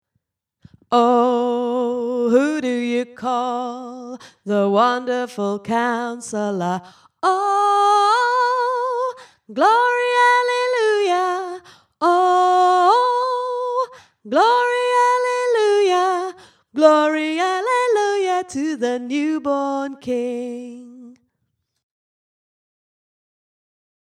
wonderful-counsellor-soprano2
wonderful-counsellor-soprano2.mp3